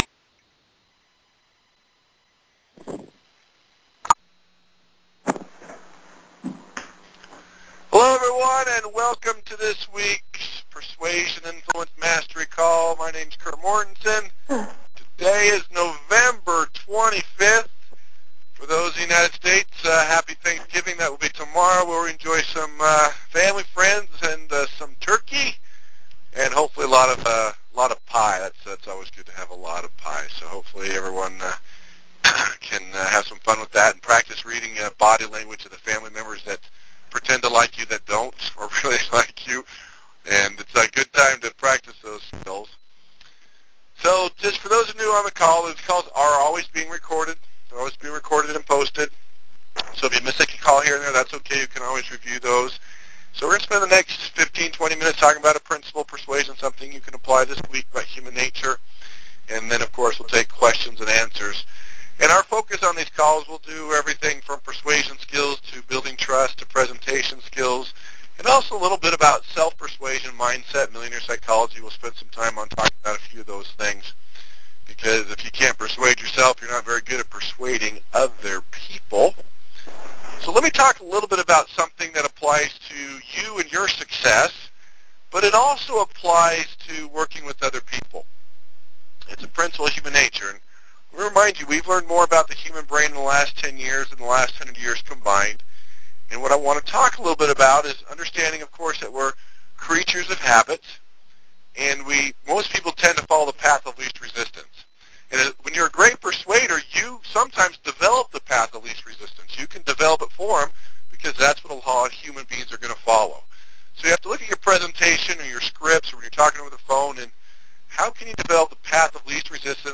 ‹ Consumer Mindset Copy writing › Posted in Conference Calls